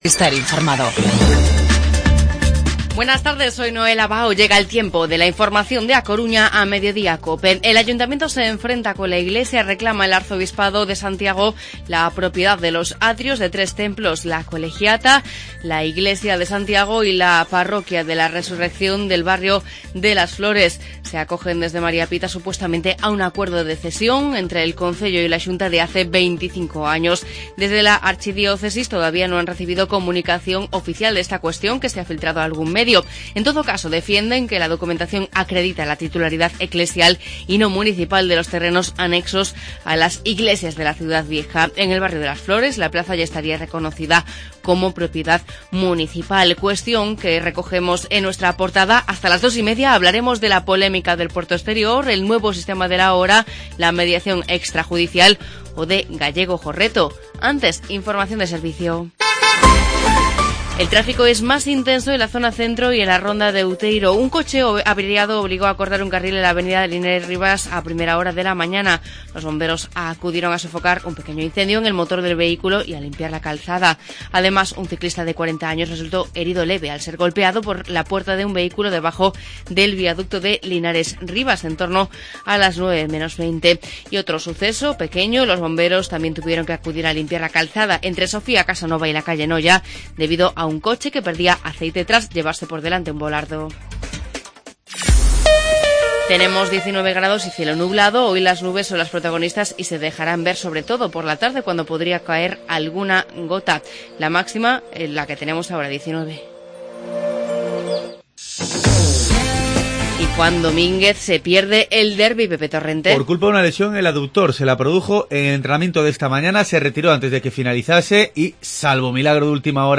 Informativo Mediodía COPE Coruña miércoles 18 de noviembre de 2015